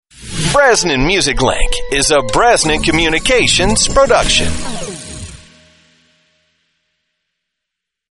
Radio Imaging & Voiceover